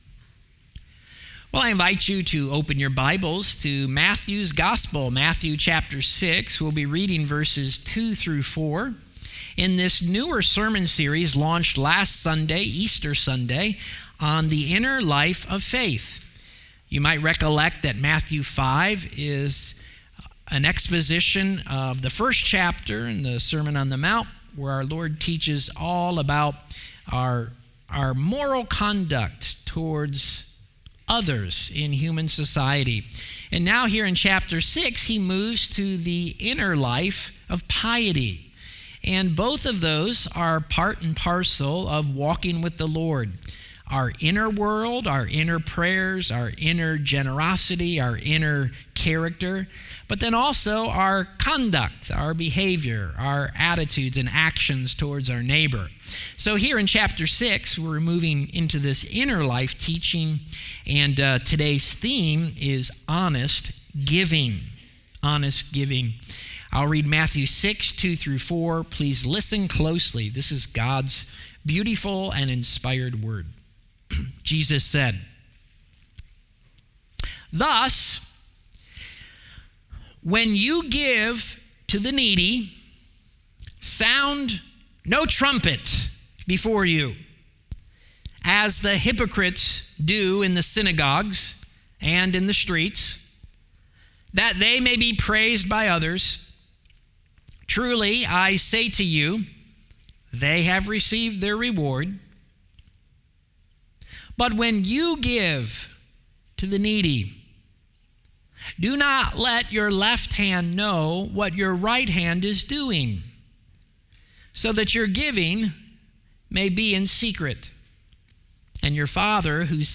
Sermons | Cedar Church